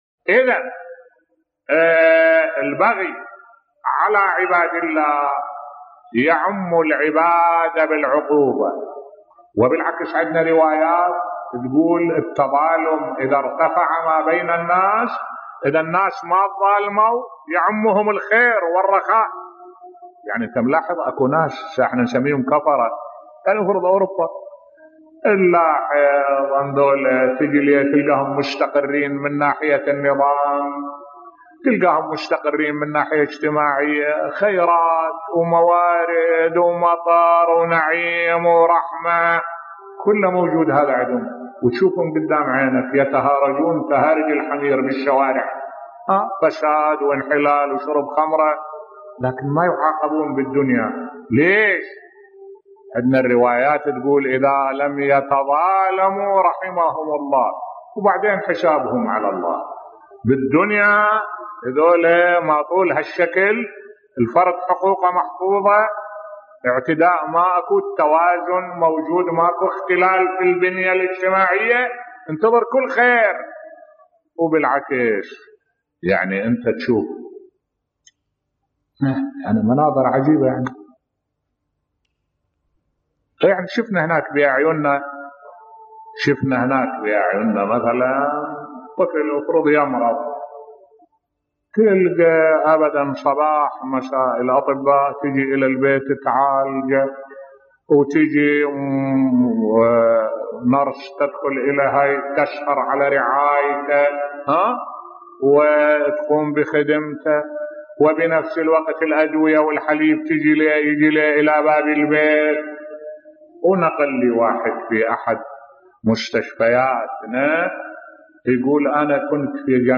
ملف صوتی لماذا لا يعجل الله العقاب للغرب بصوت الشيخ الدكتور أحمد الوائلي